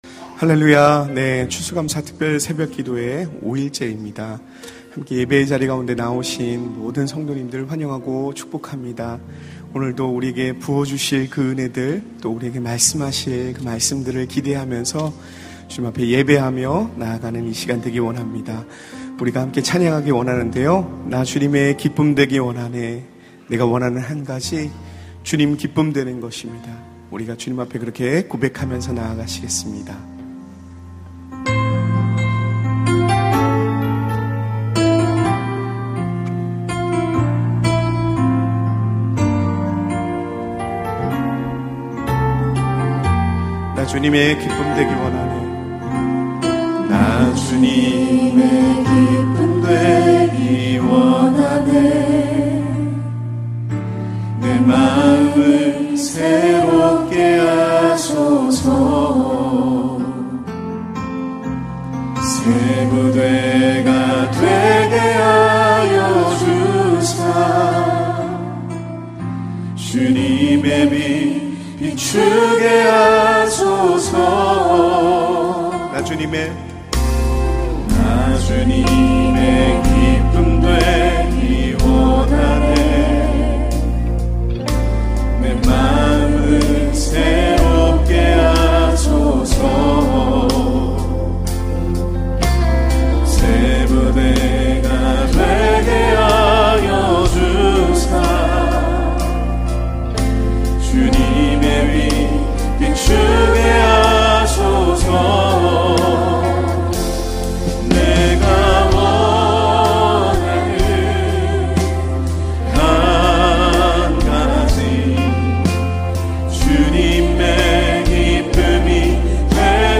집회·행사 : 절기예배